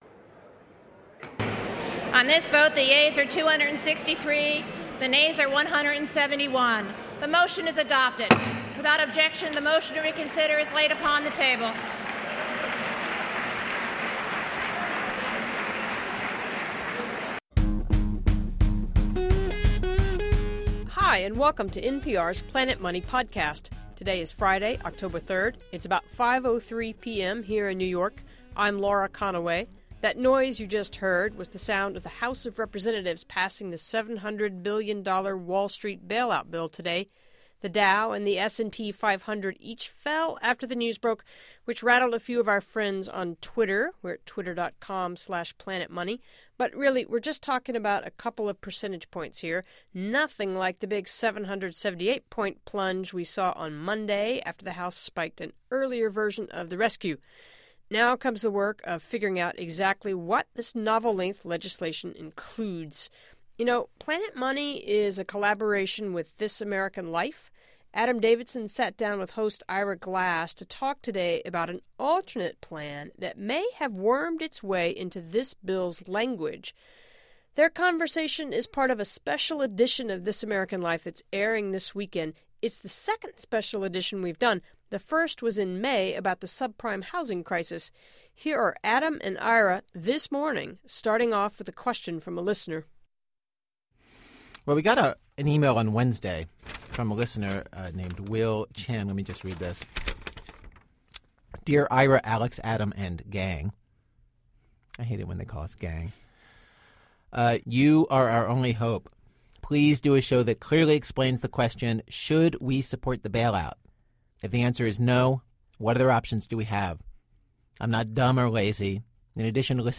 In a special appearance with This American Life host Ira Glass, Adam Davidson wrestles with the $700 billion question. Plus: A talk with an advocate for a tax break on children's wooden arrows, and a currency analyst gets gloomier after news from Europe.